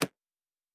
pgs/Assets/Audio/Sci-Fi Sounds/Interface/Click 3.wav
Click 3.wav